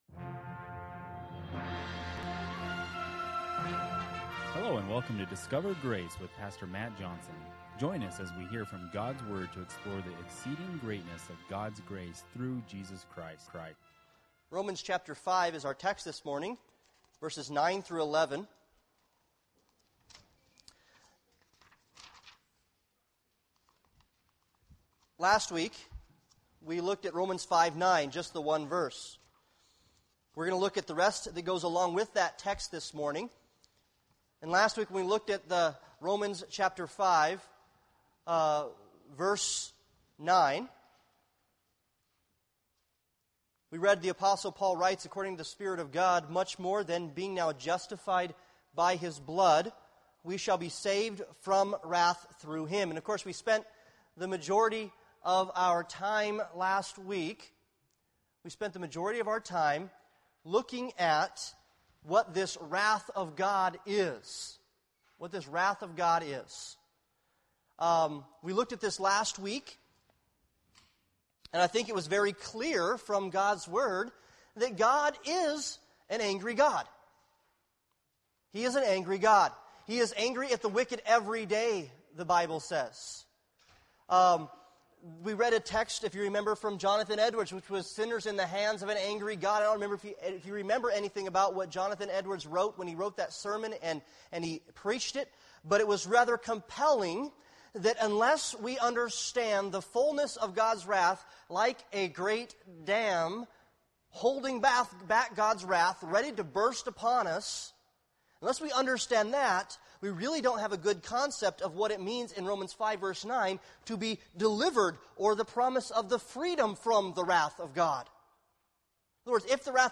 Romans 5:9-11 Service Type: Sunday Morning Worship « Motivation for Joy